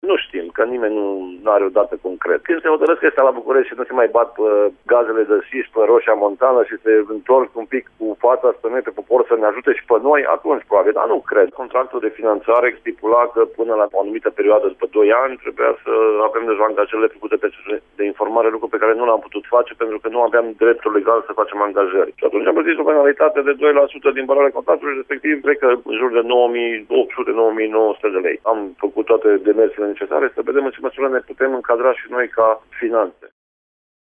În aceeaşi situaţie se află şi comuna Bozovici, al cărei primar, Adrian Stoicu, a precizat: